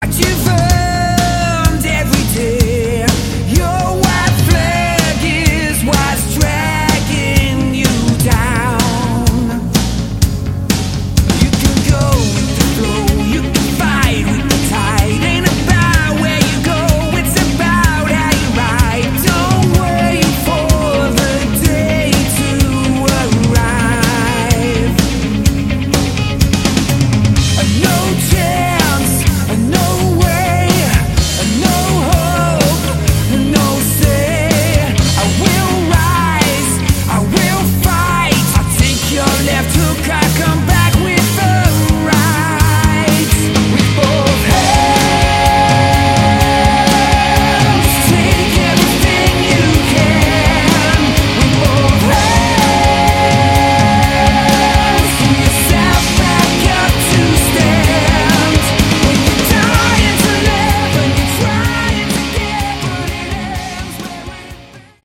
Category: Melodic Rock
vocals
drums
bass
keyboards
guitar